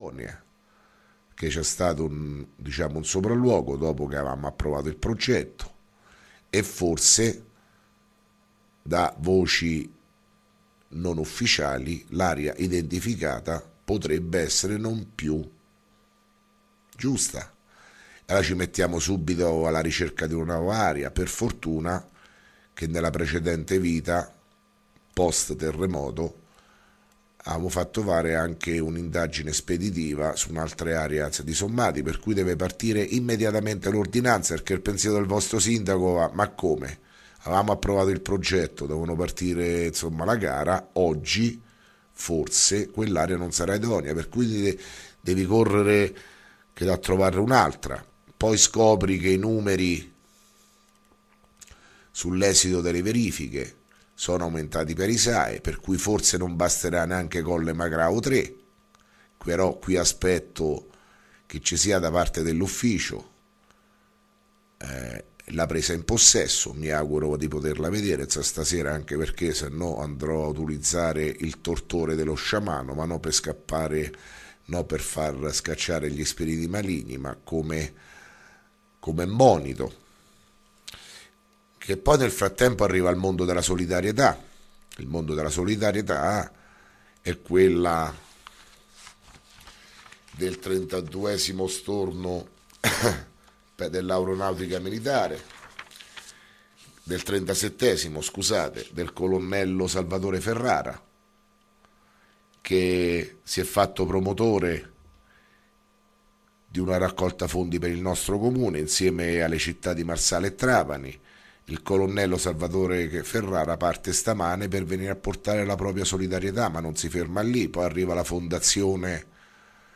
Di seguito il messaggio audio del Sindaco Sergio Pirozzi del 30 marzo 2017